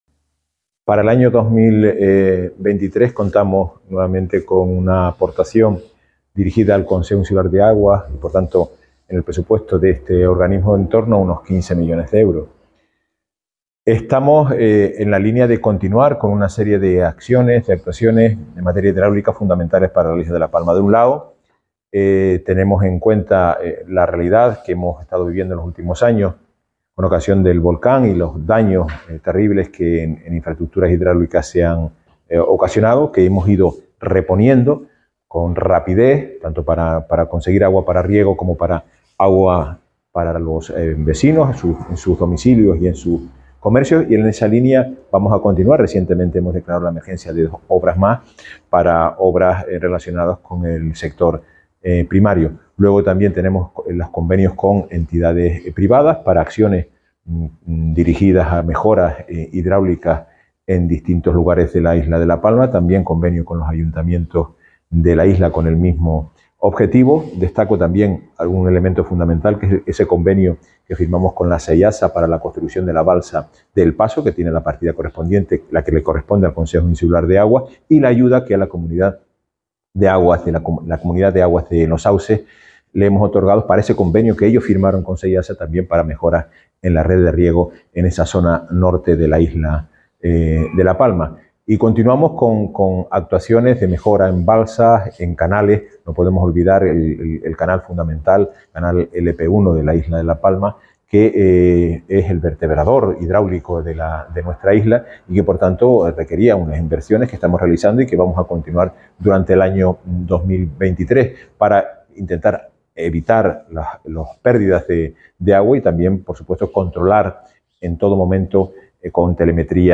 Declaraciones audio Carlos Cabrera Aguas 2023.mp3